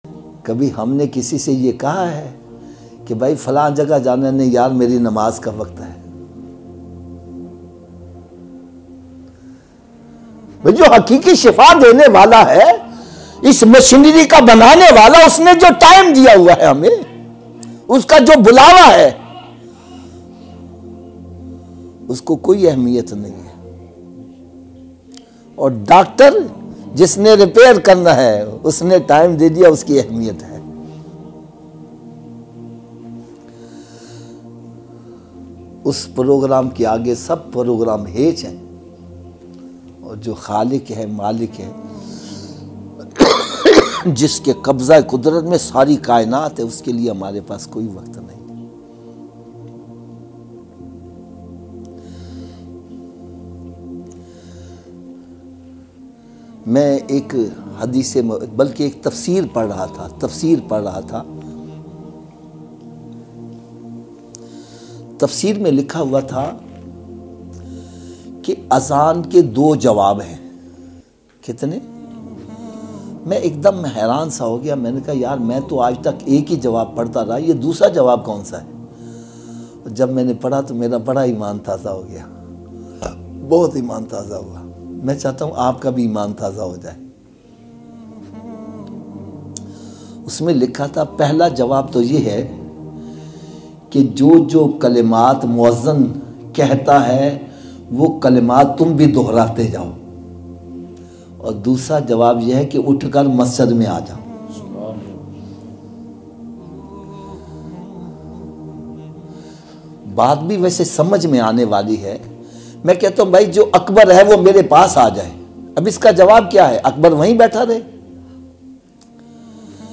emotional bayan